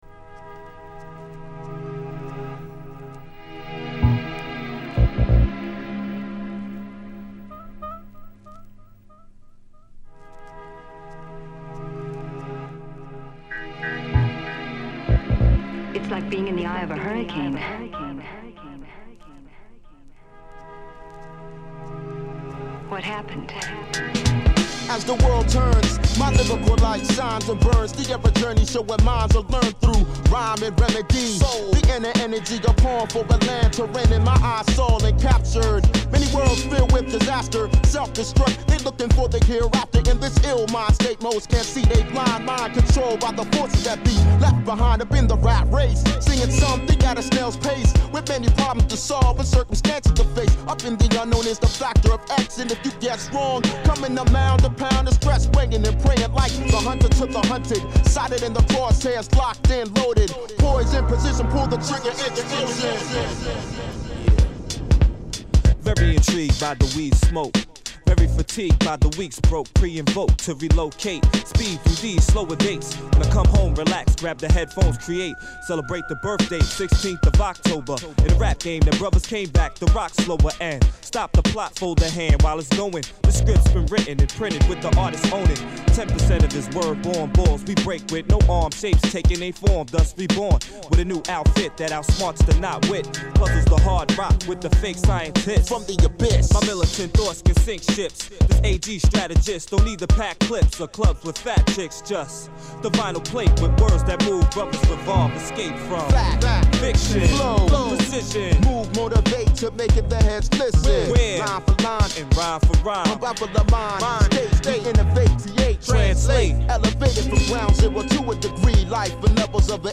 soundsci-interview.mp3